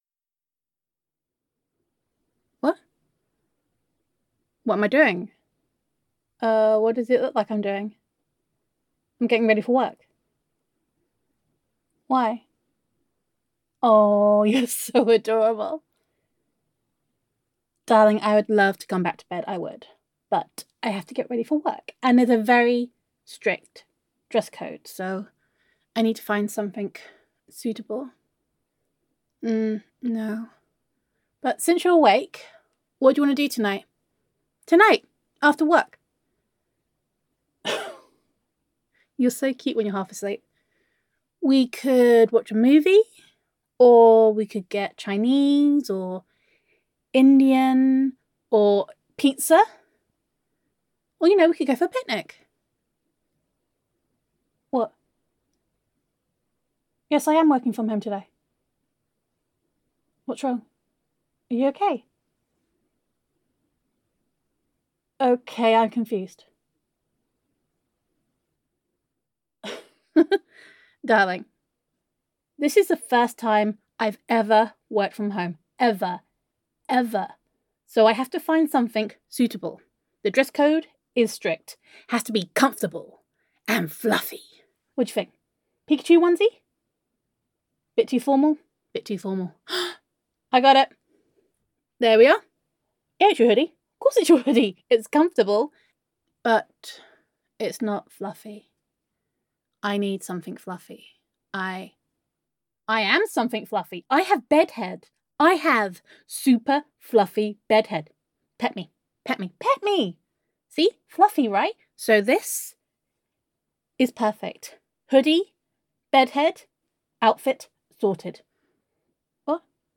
[F4A] Day One - Working From Home [Girlfriend Roleplay][Self Quarantine][Domestic Bliss][Gender Neutral][Self-Quarantine With Honey]